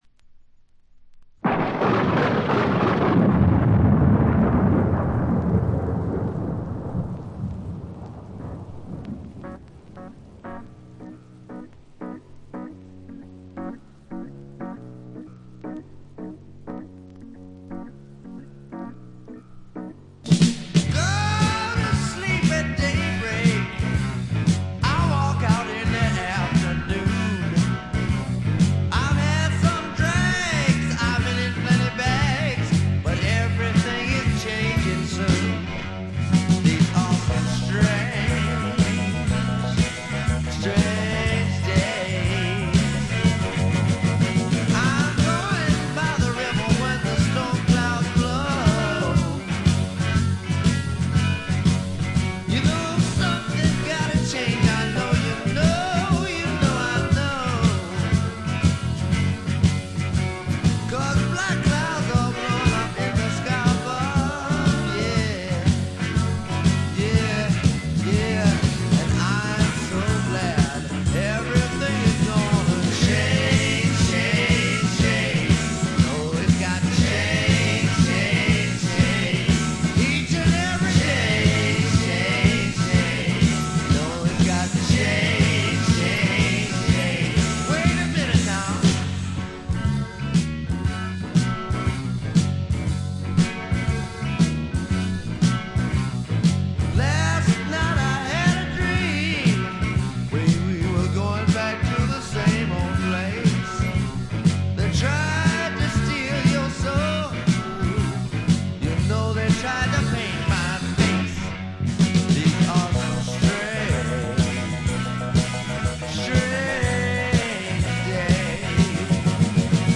部分試聴ですが、ごくわずかなノイズ感のみ。
これはもう最高のR&B／ロックンロールと言うしかないでしょう。
試聴曲は現品からの取り込み音源です。